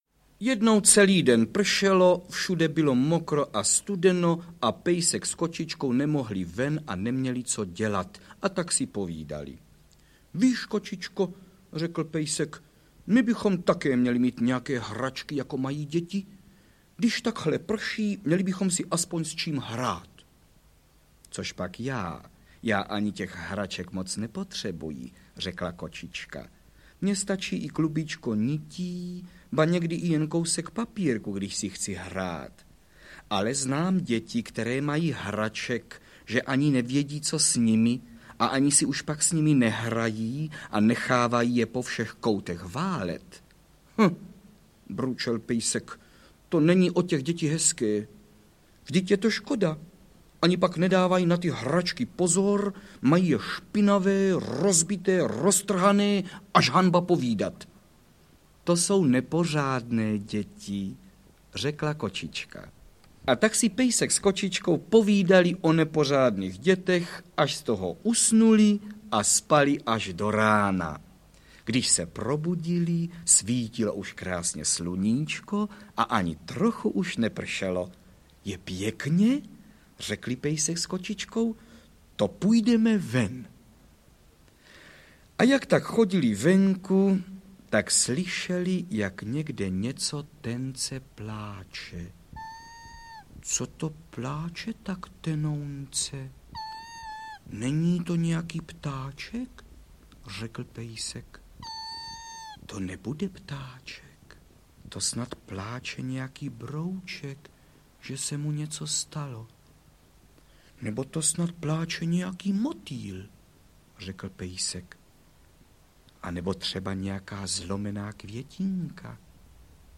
Pro desátý díl miniserie Album pohádek "Supraphon dětem" jsme připravili výběr známých i méně známých pohádek převážně vyprávěných a jednou, také méně známou, pohádkou dramatizovanou
Ukázka z knihy